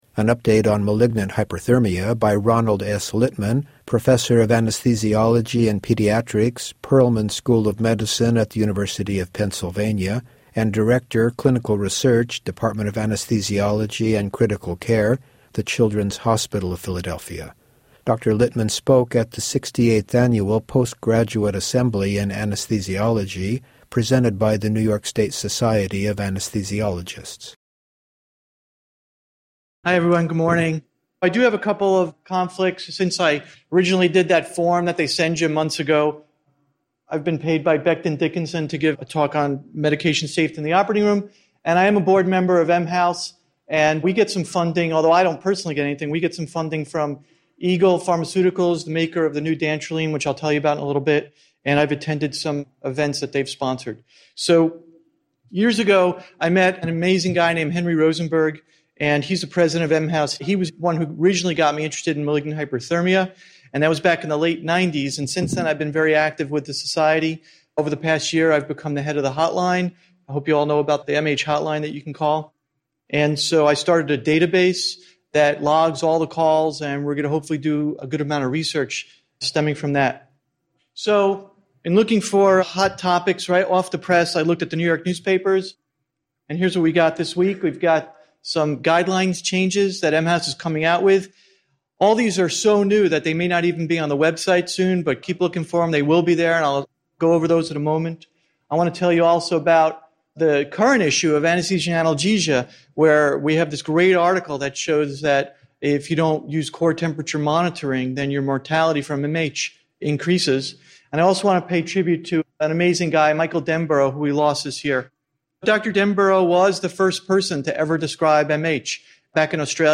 Lectures
audiodigest-recording-of-pga-conference.mp3